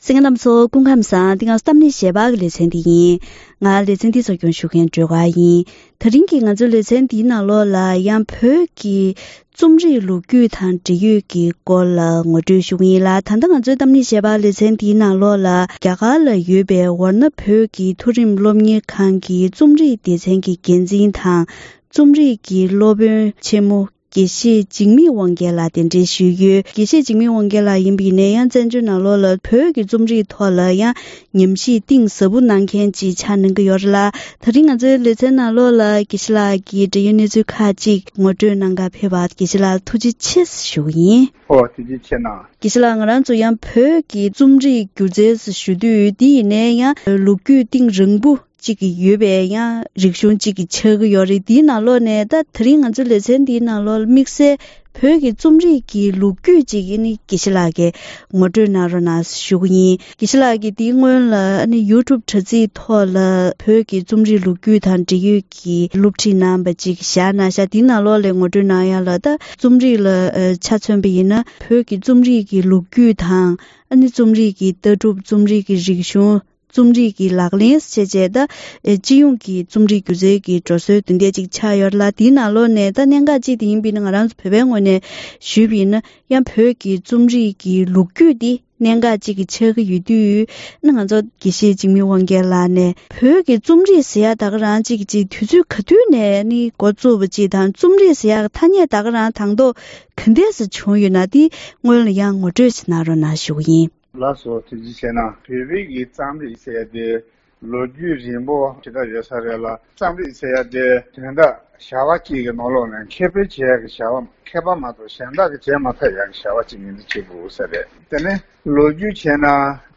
ད་རིང་གི་གཏམ་གླེང་ཞལ་པར་ལེ་ཚན་ནང་བོད་ཀྱི་རྩོམ་རིག་གི་ལོ་རྒྱུས་ཐོག་ནས་བོད་བཙན་པོའི་སྐབས་ཀྱི་རྩོམ་རིག་གི་རིན་ཐང་དང་ནུས་པ། མགུར་གྱི་ཁྱད་ཆོས། བོད་སིལ་བུར་ཐོར་ན་ཡང་བོད་ཀྱི་རྩོམ་རིག་མུ་མཐུད་གསོན་པོར་གནས་ཐུབ་པ། འགྲོ་མགོན་ཆོས་རྒྱལ་འཕགས་པ་ནས་ལོ་རབས་ལྔ་བཅུ་བར་གྱི་བོད་ཀྱི་རྩོམ་རིག་གི་འཕེལ་ཕྱོགས་དང་། ད་ལྟའི་གནས་སྟངས་ལ་སོགས་པའི་སྐོར་ལ་འབྲེལ་ཡོད་དང་ལྷན་དུ་བཀའ་མོལ་ཞུས་པ་ཞིག་གསན་རོགས་གནང་།